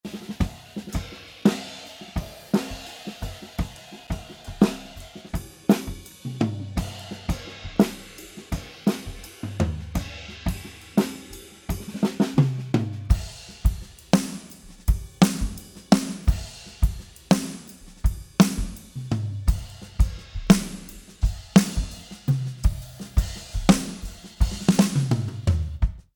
Hier mal ein Mix aus 2 Spuren: OH und SnareTop Strecke 1 = nativ Strecke 2 = Processed ohne Samples Hat 10 Minuten gedauert Dein Browser kann diesen Sound nicht abspielen.